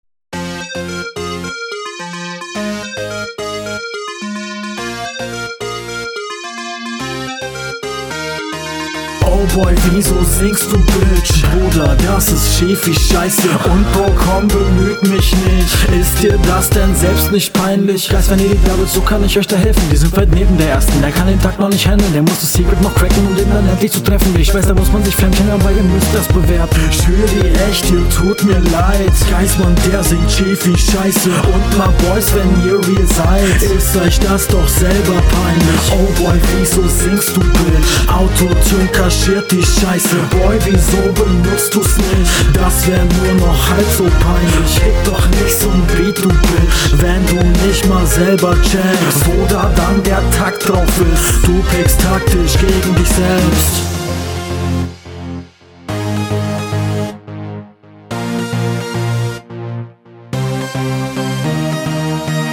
Du kommst wieder sehr angenehm auf dem Beat.